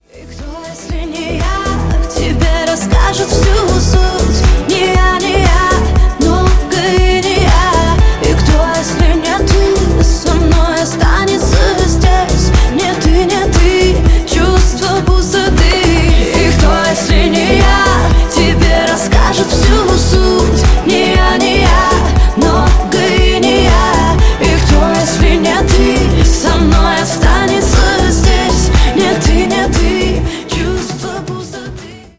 поп
женский вокал
грустные